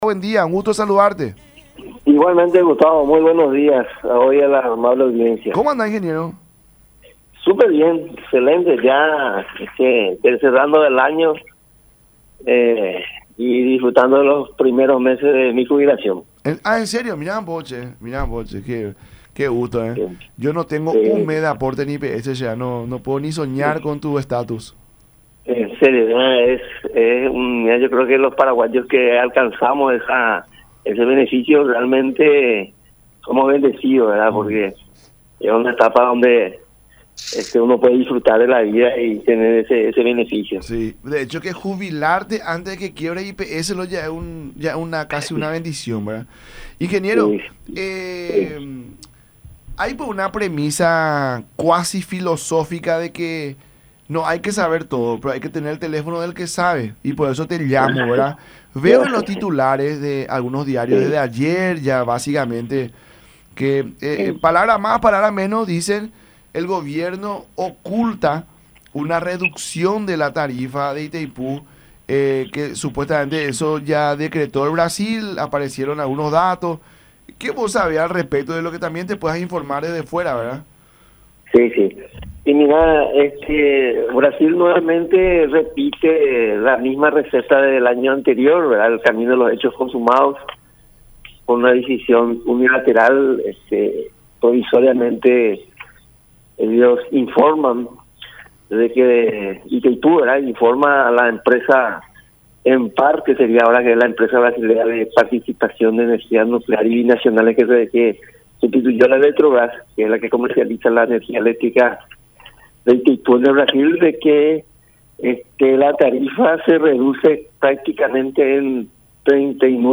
en diálogo con La Mañana De Unión a través de Unión TV y radio La Unión.